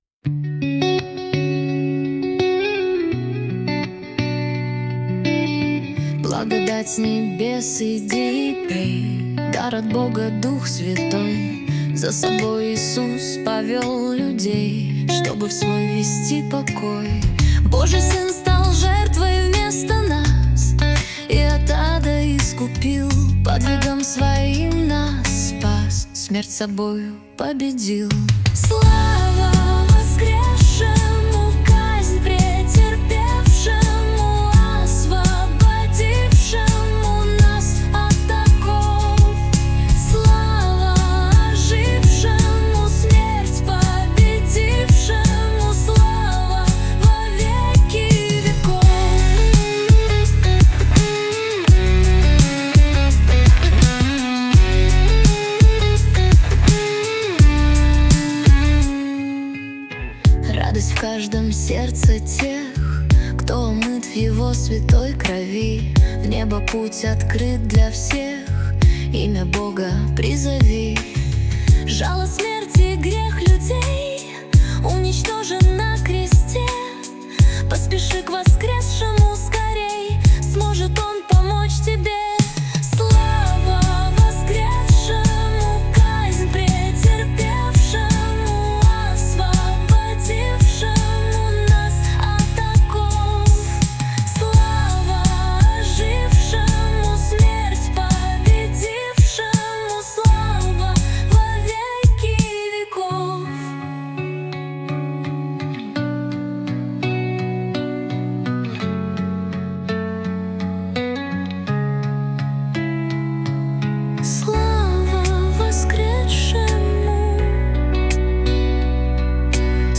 песня ai
464 просмотра 1845 прослушиваний 112 скачиваний BPM: 84